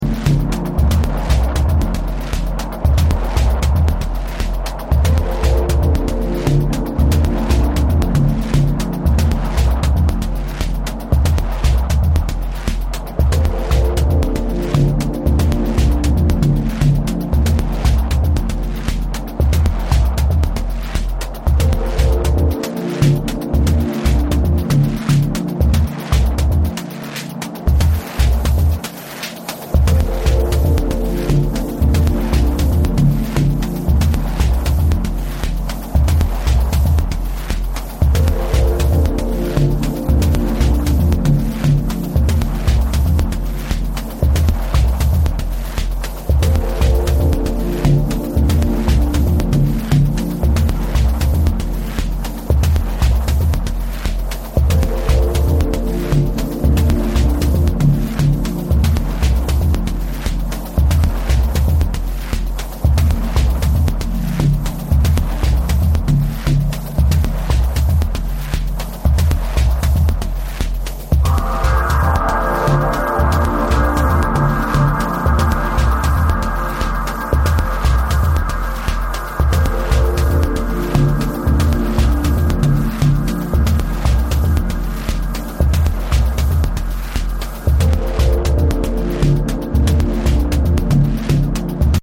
gloriously deep, beautifully produced music